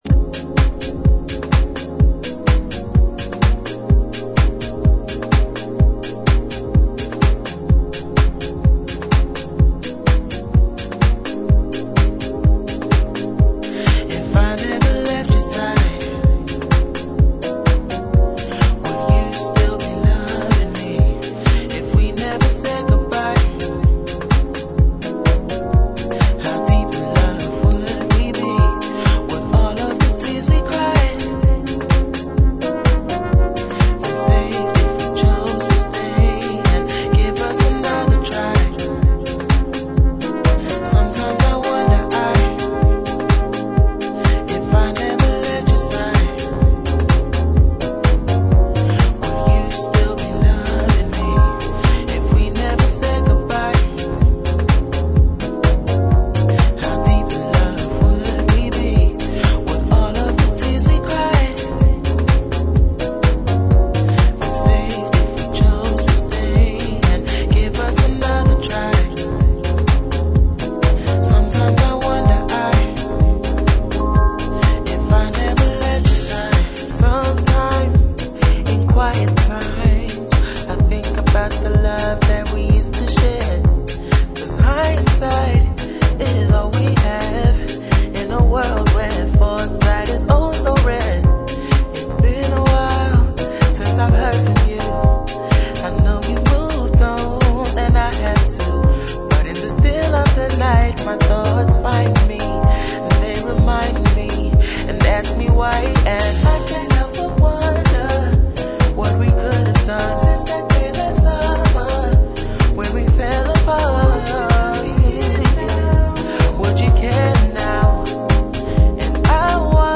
ジャンル(スタイル) HOUSE / DEEP HOUSE